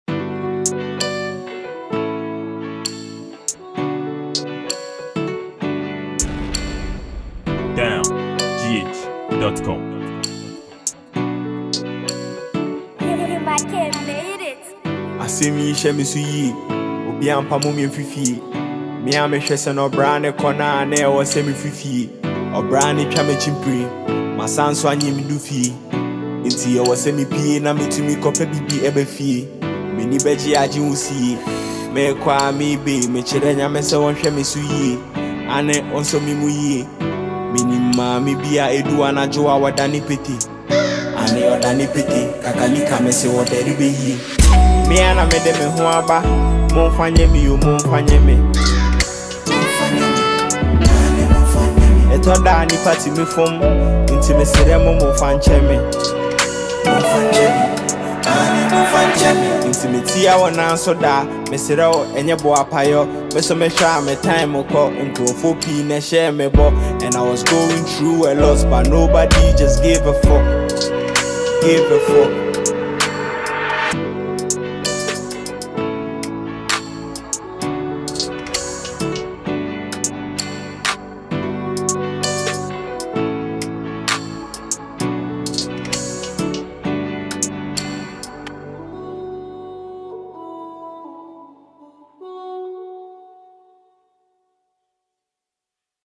Genre: Hiphop, Highlife